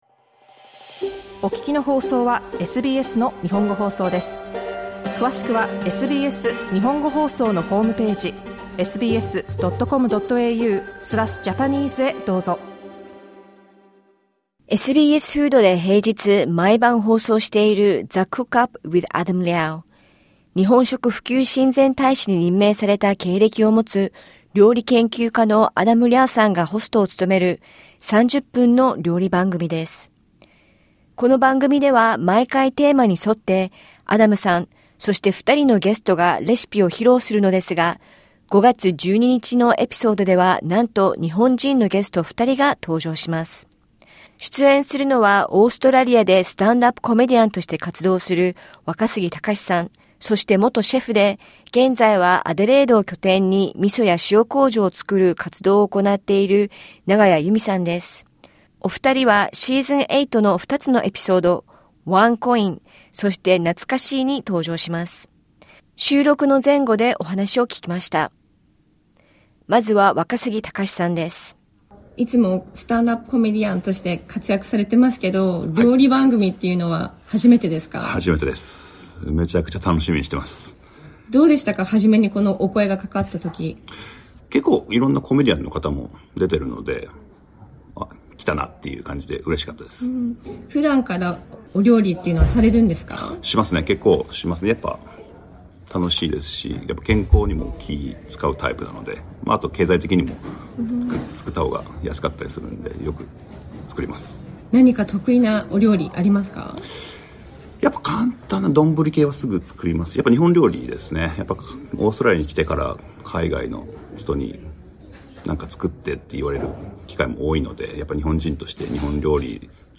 ゲストのお二人と、アダムさんに、番組の収録前後でお話しを聞きました。